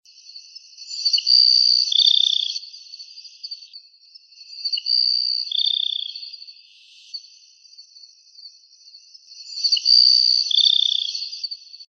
Tico-tico-do-campo (Ammodramus humeralis)
Nome em Inglês: Grassland Sparrow
Fase da vida: Adulto
Detalhada localização: Estancia San Juan Poriahú
Condição: Selvagem
Certeza: Fotografado, Gravado Vocal